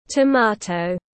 Tomato /tə’mɑ:tou/